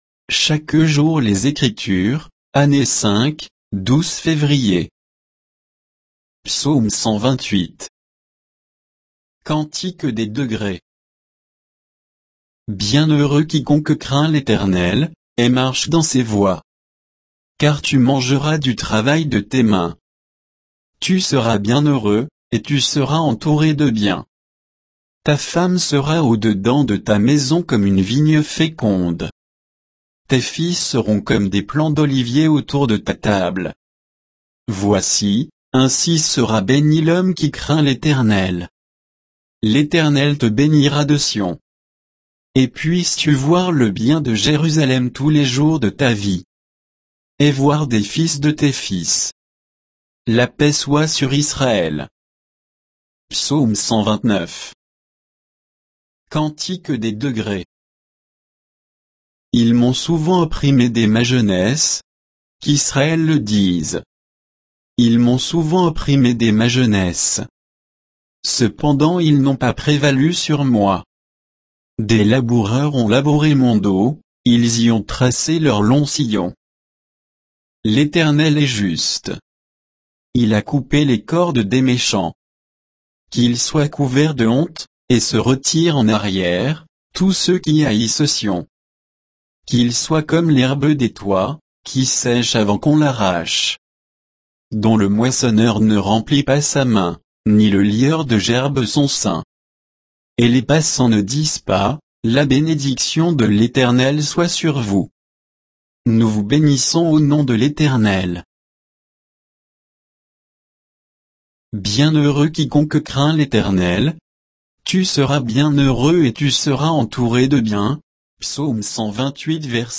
Méditation quoditienne de Chaque jour les Écritures sur Psaumes 128 et 129